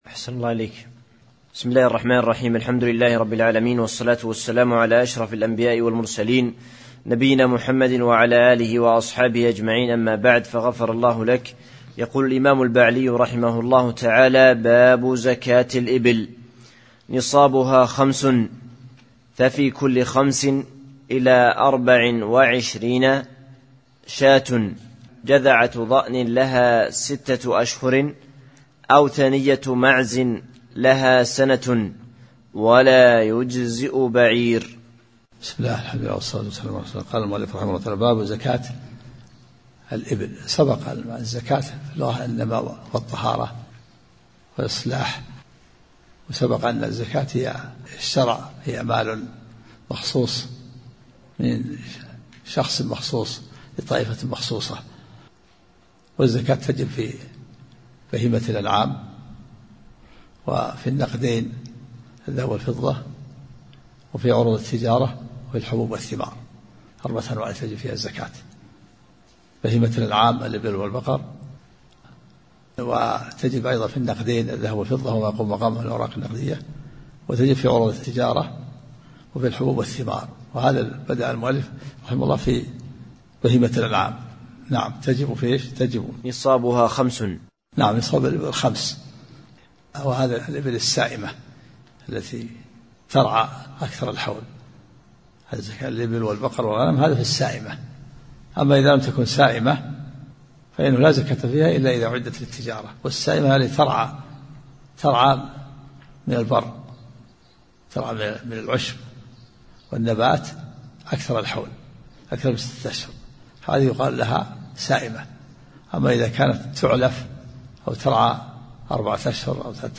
سلسلة محاضرات صوتية